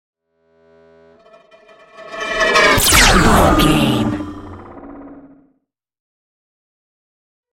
Sci fi shot whoosh to hit
Sound Effects
futuristic
woosh to hit